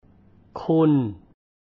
"Khun" being another transcription sounding like khoon [note to our English friends].